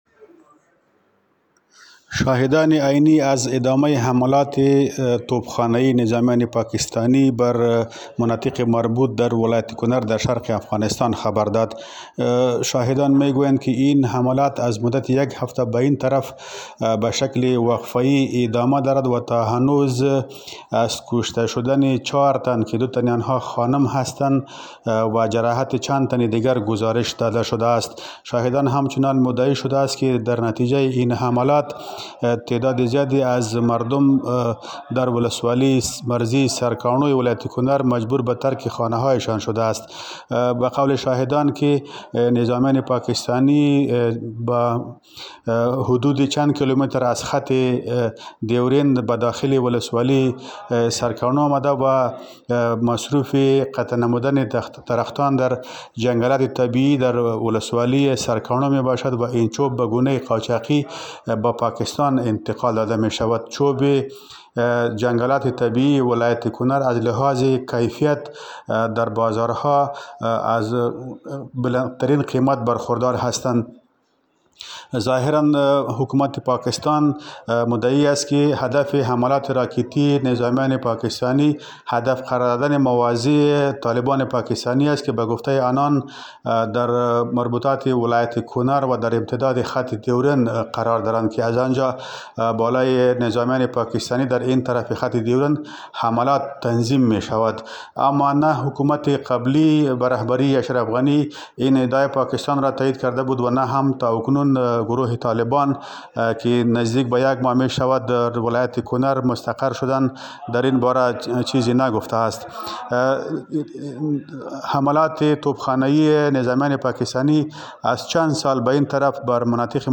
در گزارشی جزییات بیشتری از این خبر را بازگو می کند.